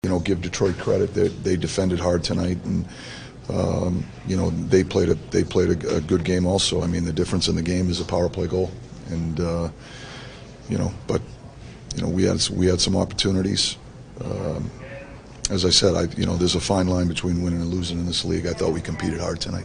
Coach Mike Sullivan says it was a solid hockey game by both teams.